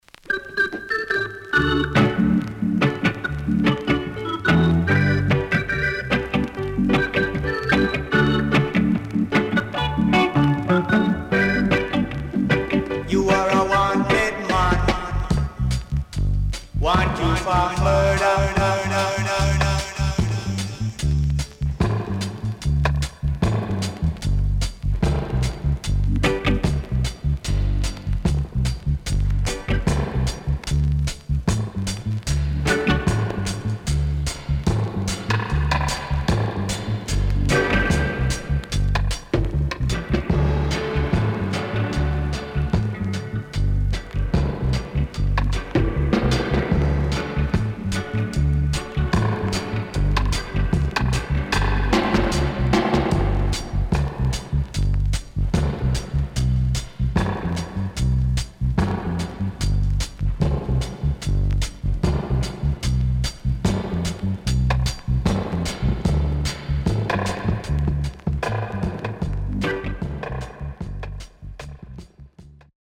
CONDITION SIDE A:VG(OK)
Nice Vocal & Dubwise
SIDE A:全体的に薄くチリノイズがあり、少しプチノイズ入ります。